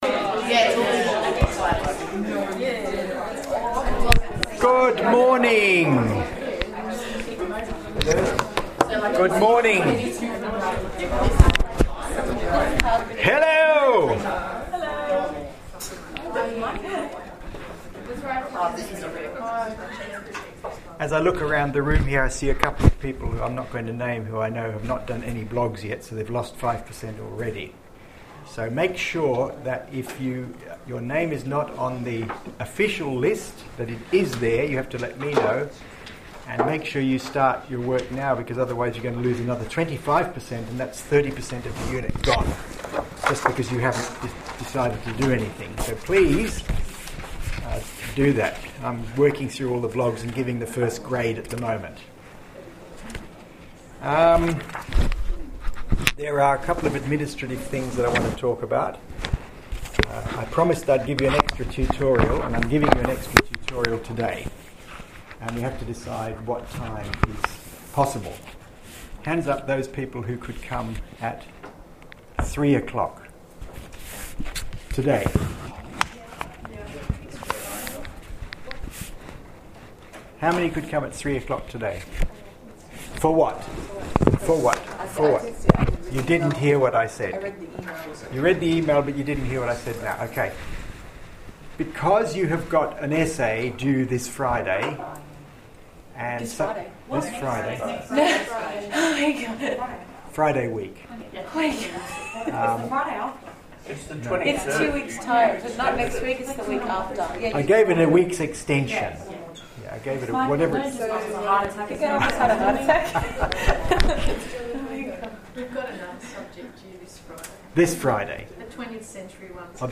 blake-lecture-week6.mp3